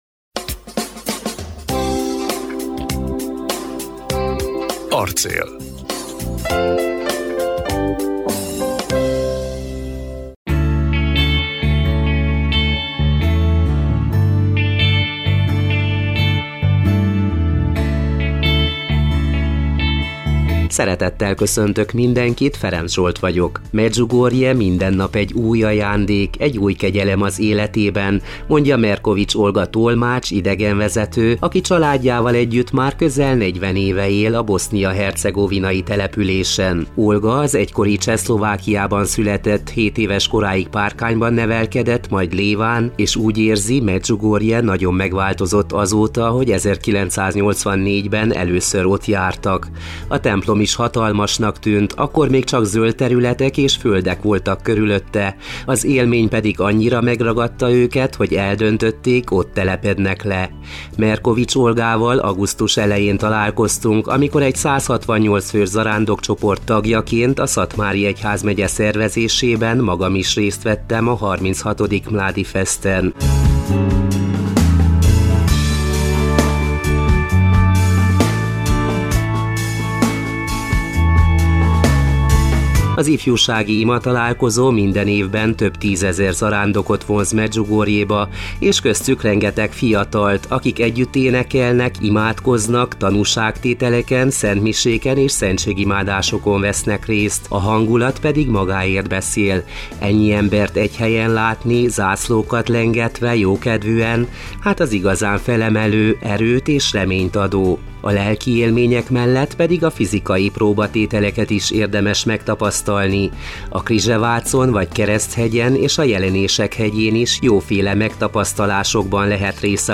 Ezeket is a bosznia-hercegovinai Medjugorjéban rögzítettük, az ifjúsági imatalálkozó idején, akárcsak a szatmárnémeti Szentlélek-plébánia énekkarosainak produkcióit – adja Isten, hogy ezek is hozzásegítsenek az elmélyüléshez!